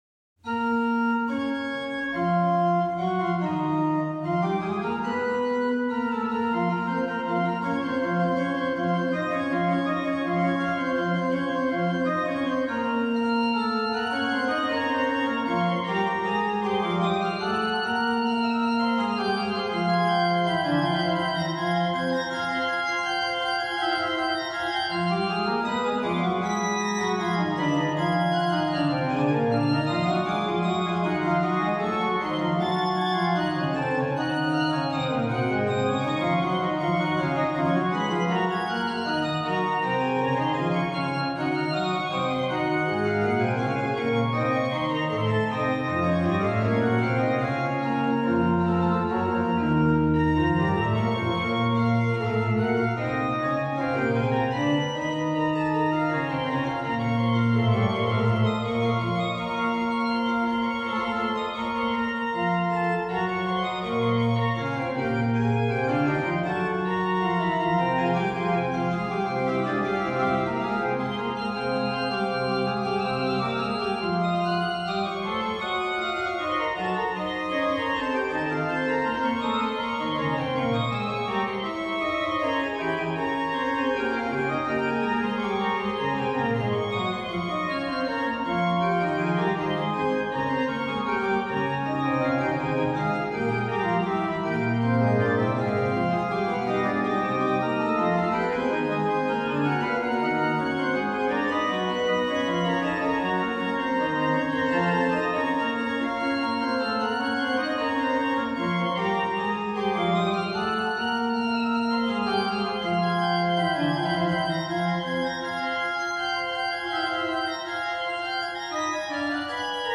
Registration   Ged8, Oct4, Oct2, 1 1/2